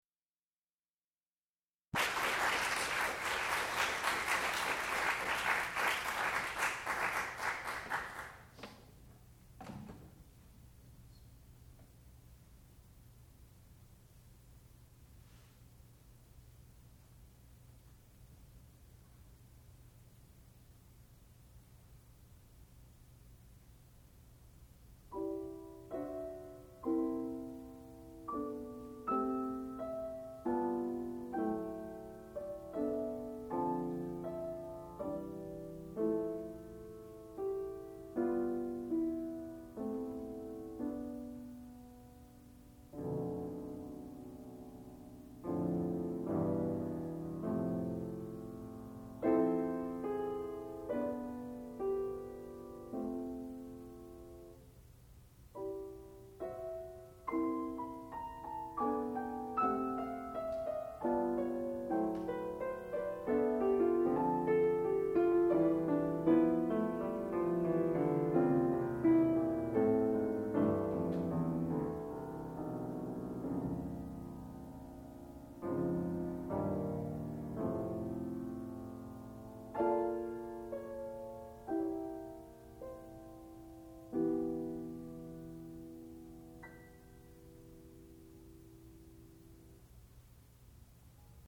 sound recording-musical
classical music
piano